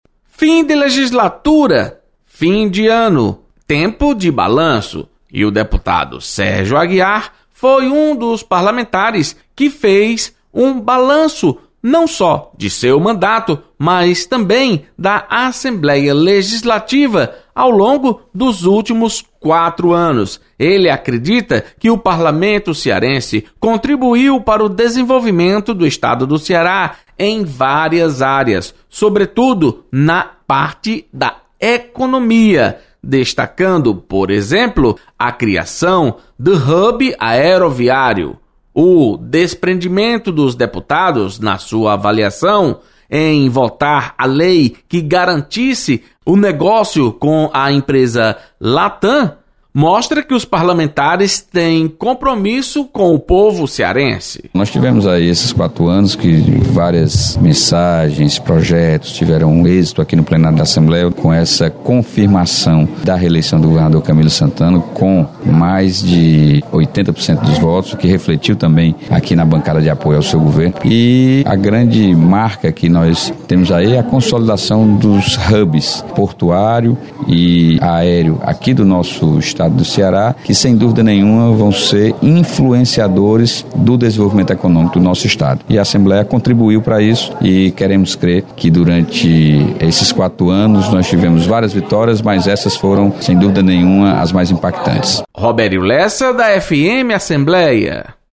Deputado Sérgio Aguiar destaca papel da Assembleia no avanço do Ceará em várias áreas. Repórter